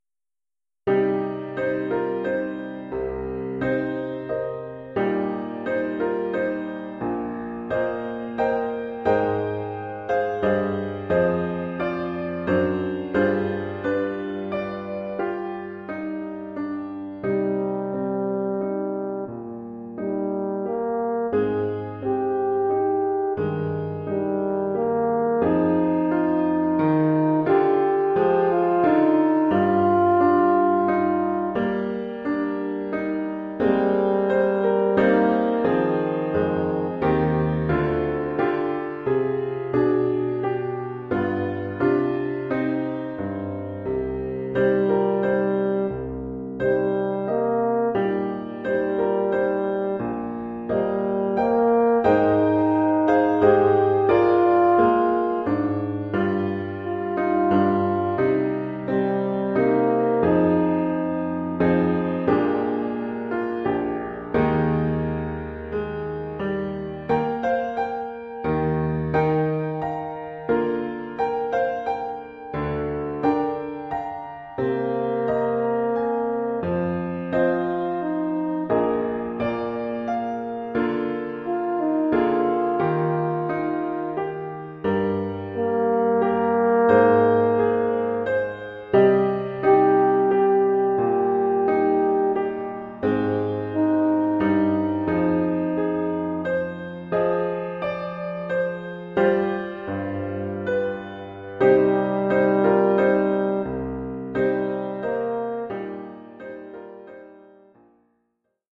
Oeuvre pour cor naturel mib et piano.